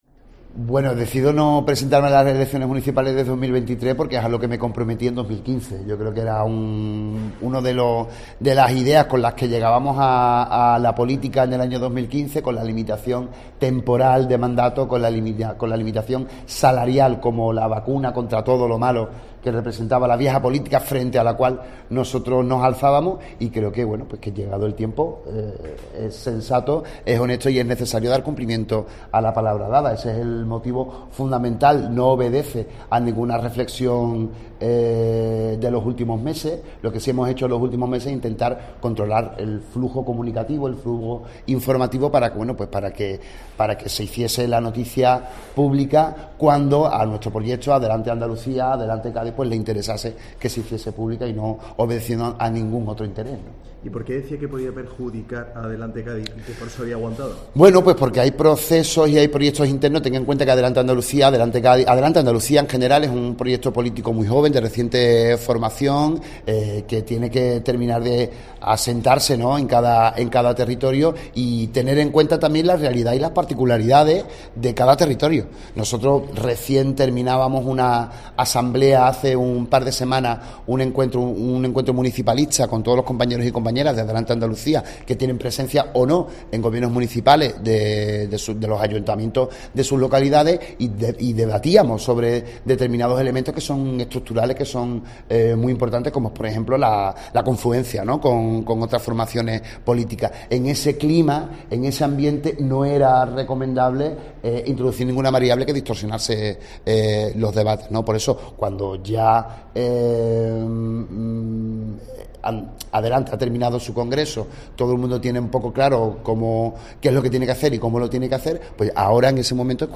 Declaraciones de el alcalde de Cádiz, José Mª González sobre su renuncia a la reelección